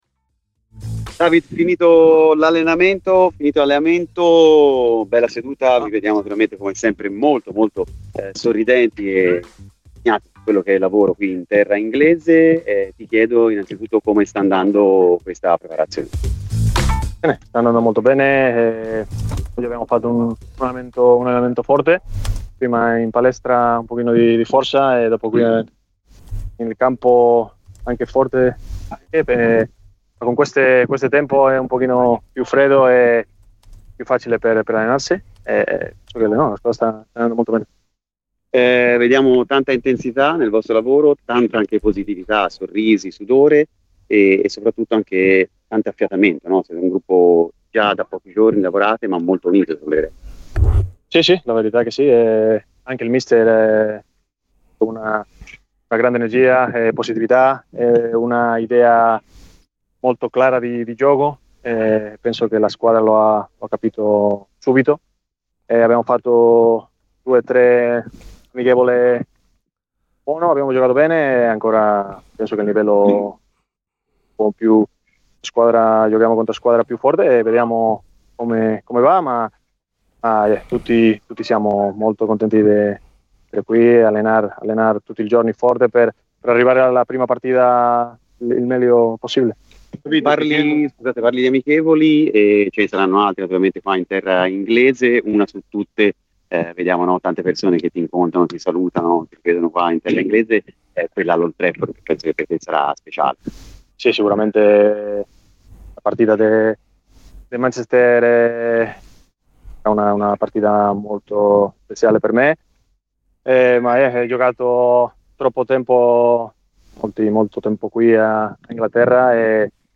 Secondo giorno di preparazione in Inghilterra per la Fiorentina e secondo allenamento al centro tecnico federale della Nazionale inglese per la squadra di Pioli.